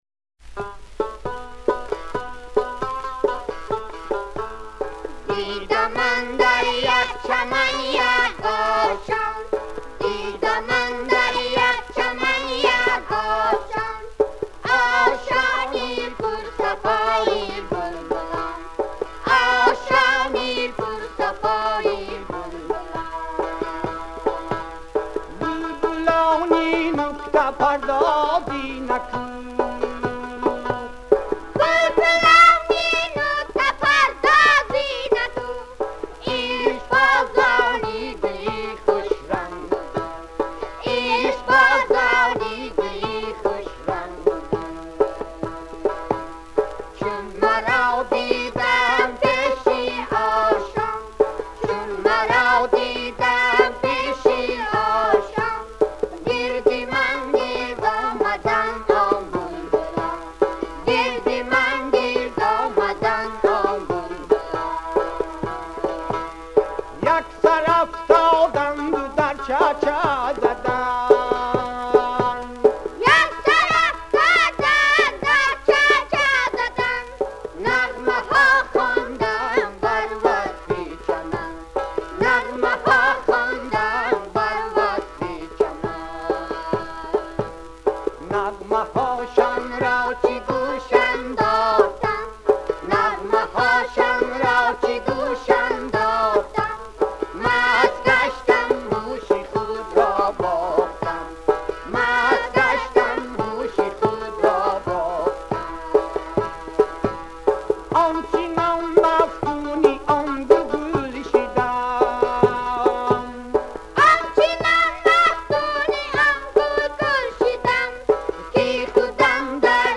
Genre: Early Ethnic